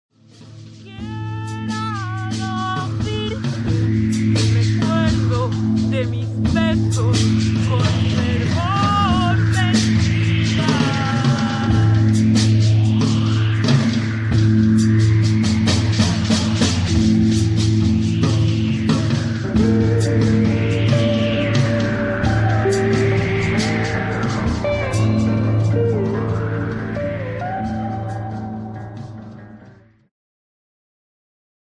Segment Jazz-Rock
Progressive